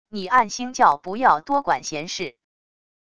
你暗星教不要多管闲事wav音频生成系统WAV Audio Player